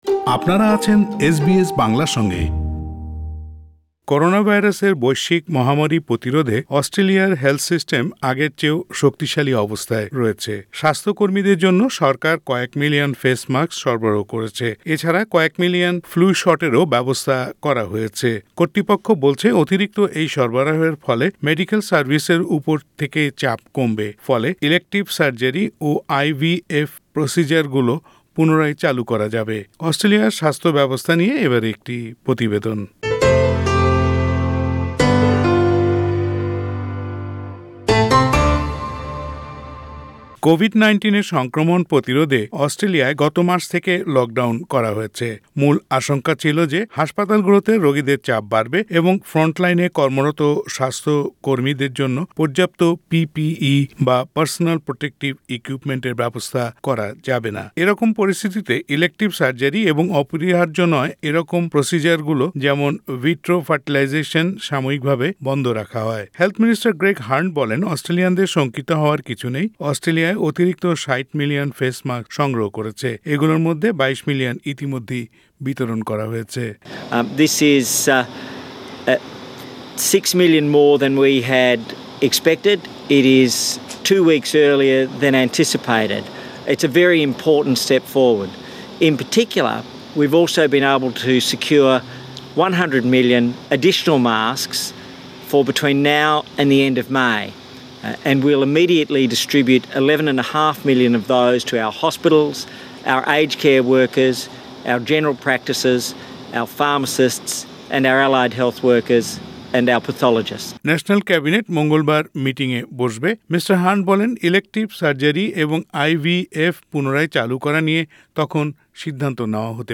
অস্ট্রেলিয়ার স্বাস্থ্য-ব্যবস্থা নিয়ে প্রতিবেদনটি শুনতে উপরের অডিও প্লেয়ারের লিংকটিতে ক্লিক করুন ।